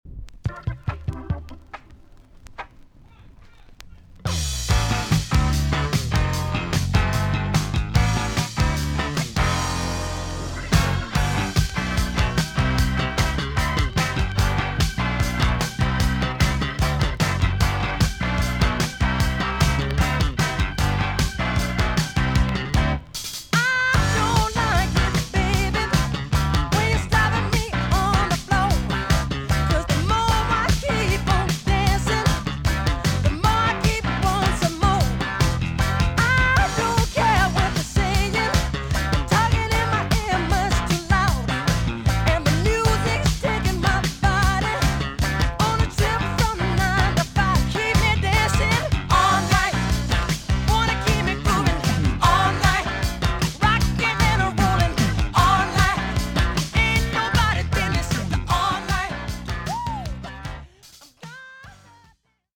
EX-音はキレイです。